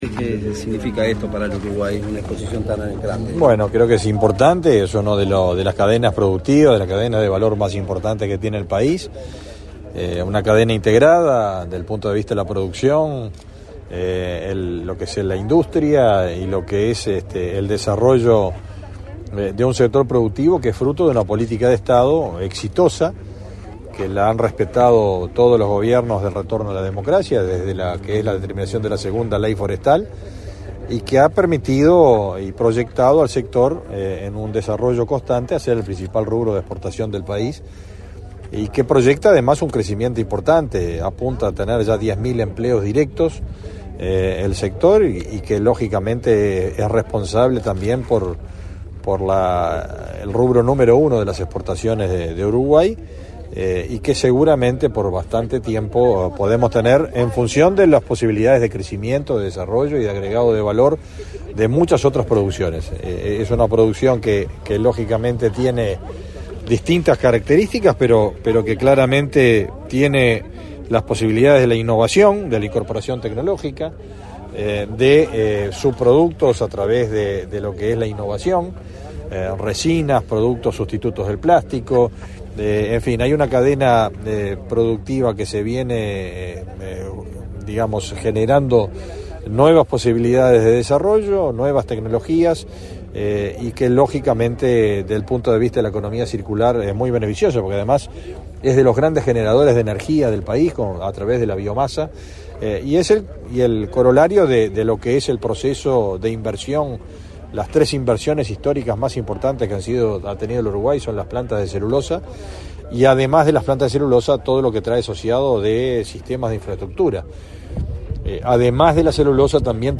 Declaraciones a la prensa del titular del MGAP, Fernando Mattos
Declaraciones a la prensa del titular del MGAP, Fernando Mattos 10/10/2024 Compartir Facebook X Copiar enlace WhatsApp LinkedIn Con la presencia del presidente de la República, Luis Lacalle Pou, se realizó, este 10 de octubre, la Feria Forestal, del Agro y el Ambiente, en la ciudad de Juan Lacaze, en Colonia. Previo al evento, el titular del Ministerio de Ganadería, Agricultura y Pesca (MGAP), Fernando Mattos, realizó declaraciones a la prensa.